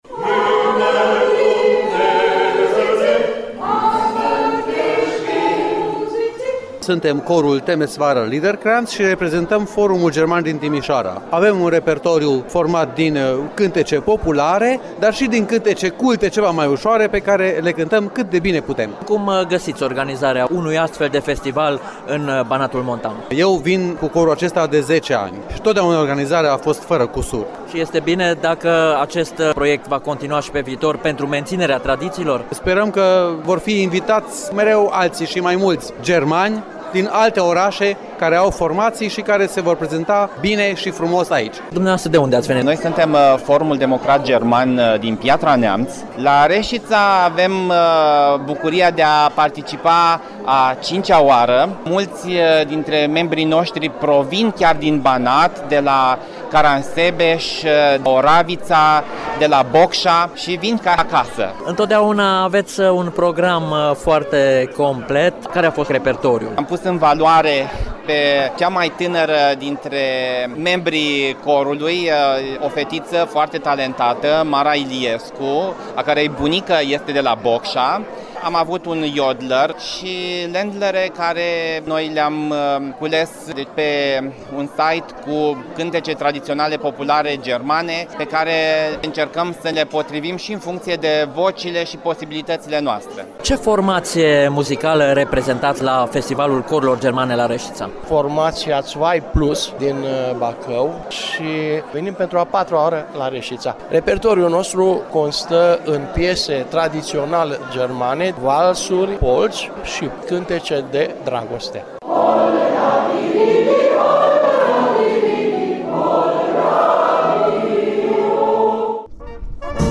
[AUDIO] Corurile şi fanfarele şi-au dat întâlnire în Banatul de munte